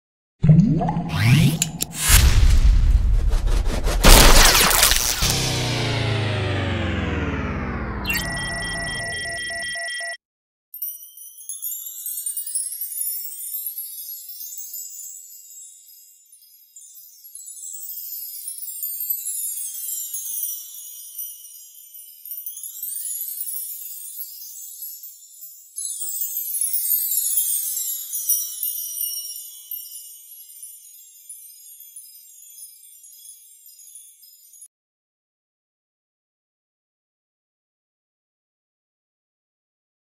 دانلود آهنگ ستاره بارون شدن از افکت صوتی طبیعت و محیط
جلوه های صوتی
برچسب ها: دانلود آهنگ های افکت صوتی طبیعت و محیط